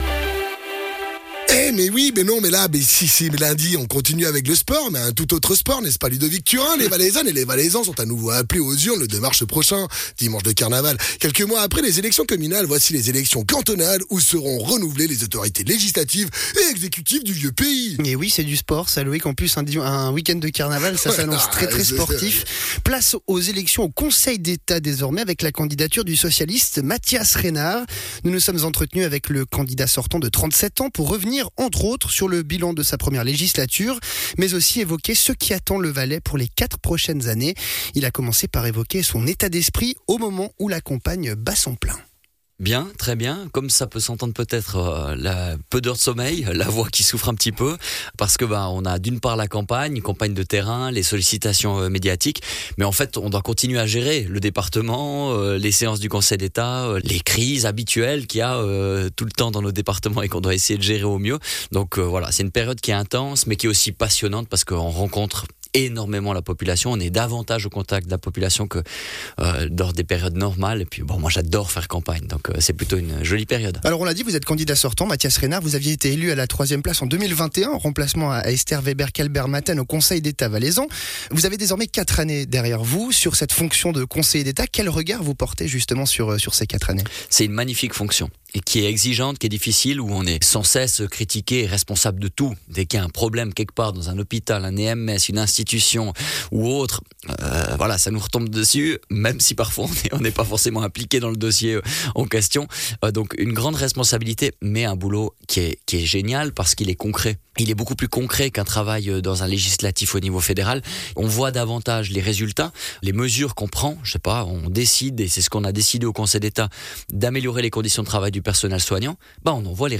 Elections cantonales 2025: entretien avec Mathias Reynard
Intervenant(e) : Mathias Reynard